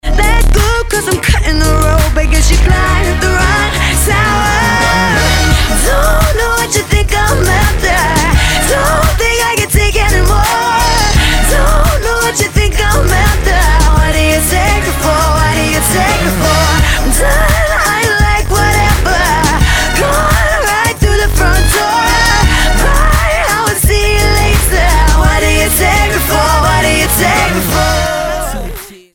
Iba 20-ročná anglická speváčka a herečka